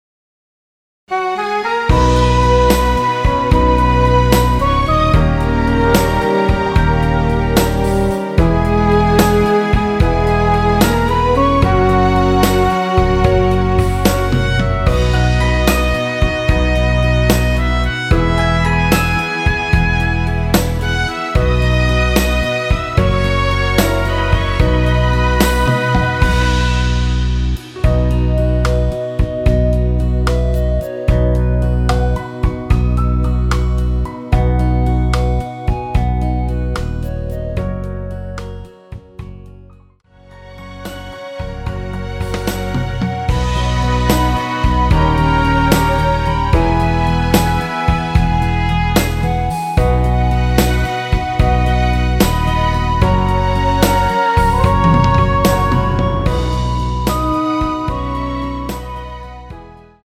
원키에서(+5)올린 멜로디 포함된 MR입니다.(미리듣기 확인)
음질 깨끗하고, 만족합니다.
앞부분30초, 뒷부분30초씩 편집해서 올려 드리고 있습니다.